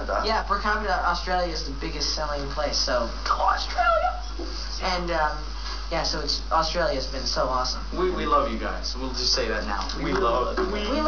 *Click* on the flag to hear Hanson proclaiming their love for the land downunder! (Hey Hey '98)